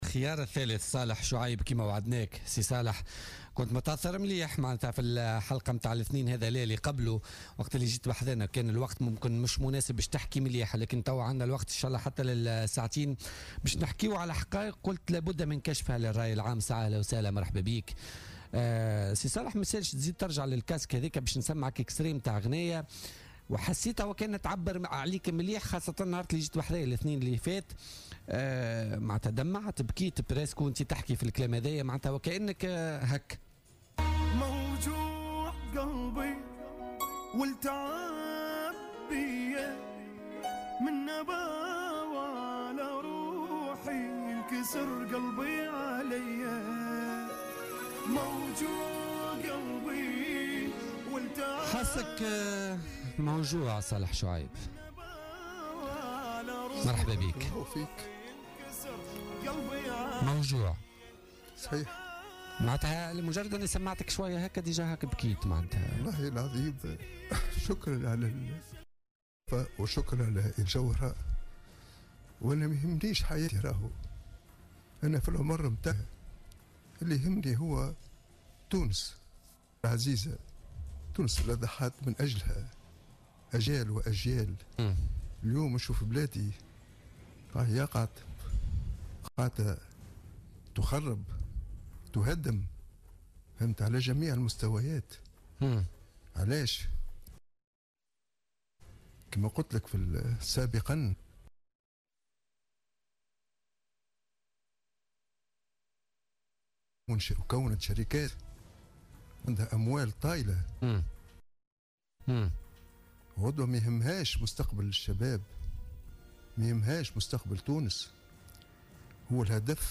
يبكي في المباشر